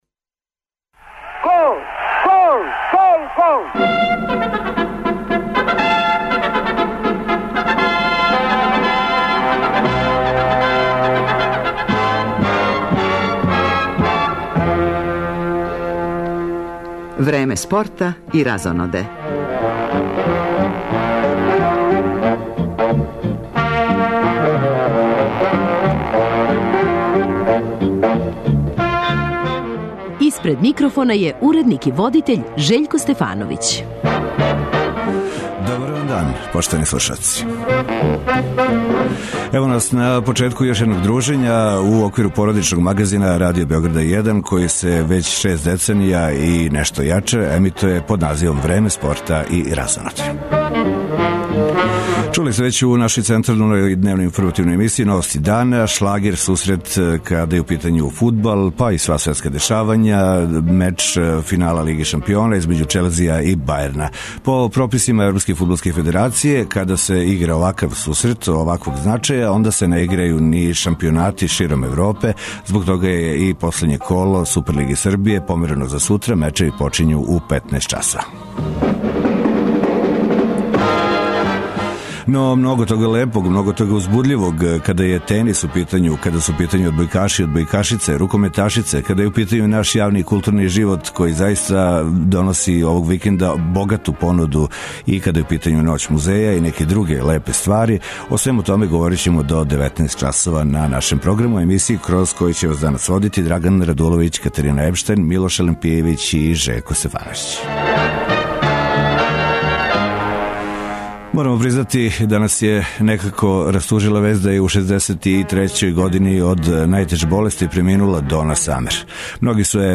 У Зајечару се овог викенда игра и финале Купа за рукометашице, па ћемо током емисије чути изјаве представница тимова који играју у одлучујућем мечу.